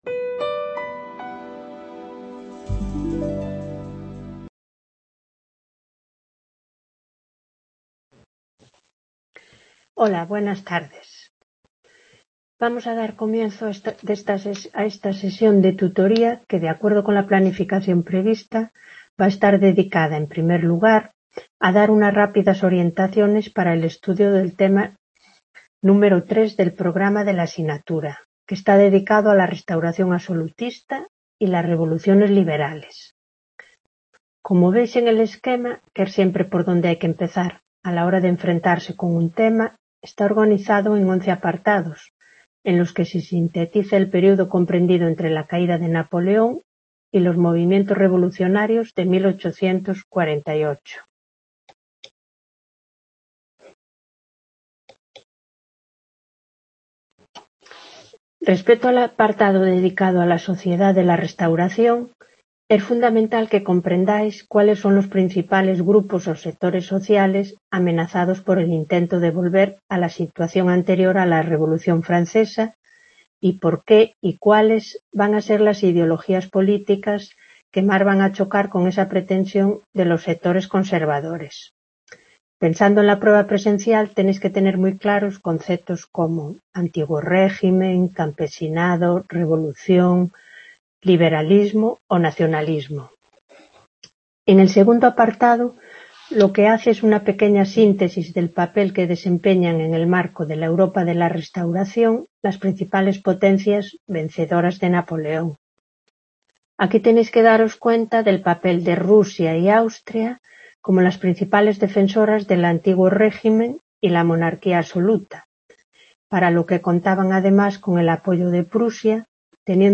Grabación de una clase | Repositorio Digital